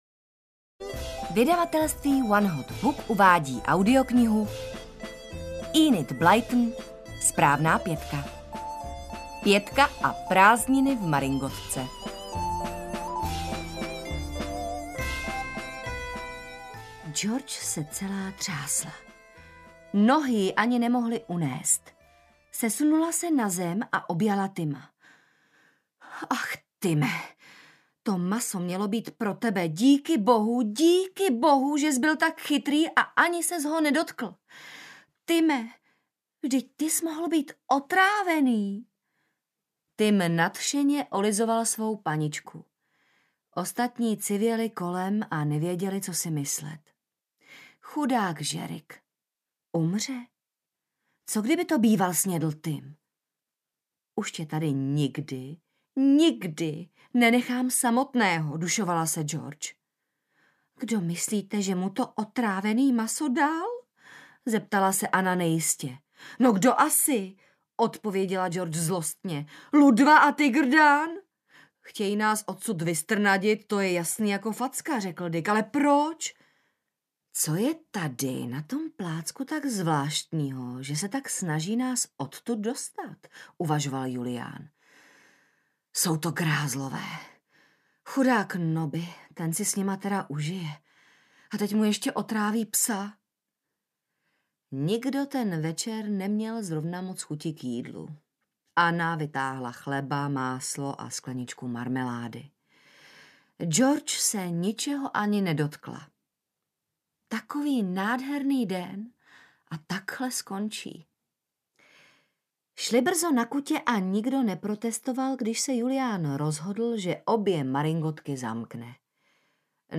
SPRÁVNÁ PĚTKA a prázdniny v maringotkách audiokniha
Ukázka z knihy